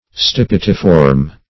Search Result for " stipitiform" : The Collaborative International Dictionary of English v.0.48: Stipitiform \Stip"i*ti*form\, a. [Stipes + -form.]